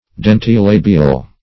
Meaning of dentilabial. dentilabial synonyms, pronunciation, spelling and more from Free Dictionary.
Search Result for " dentilabial" : The Collaborative International Dictionary of English v.0.48: Dentilabial \Den`ti*la"bi*al\, a. Formed by the teeth and the lips, or representing a sound so formed.